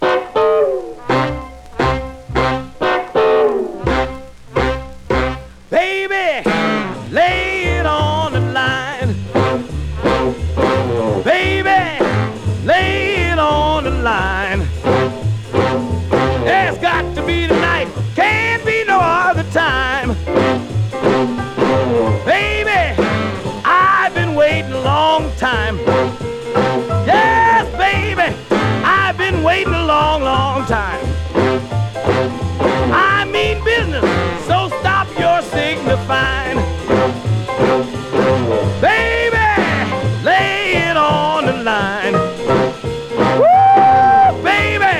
Jazz, Rhythm & Blues, Jump　Sweden　12inchレコード　33rpm　Mono